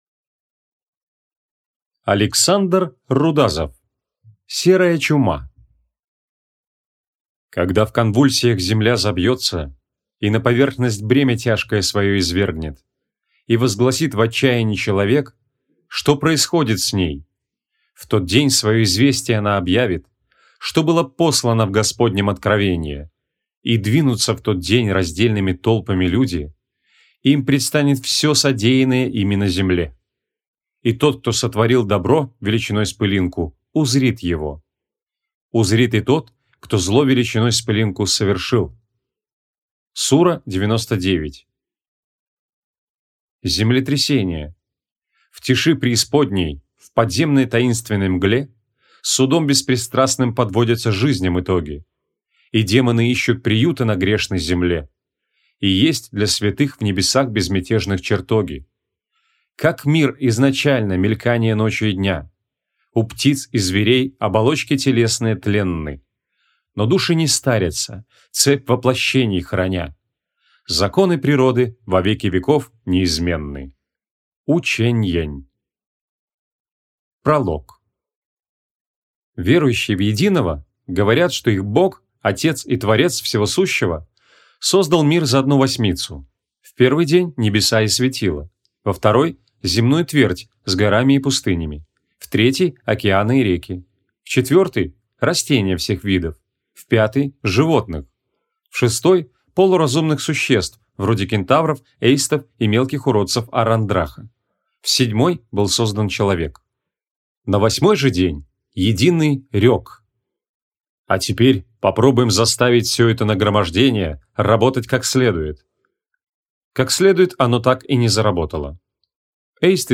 Аудиокнига Серая чума | Библиотека аудиокниг
Aудиокнига Серая чума Автор Александр Рудазов.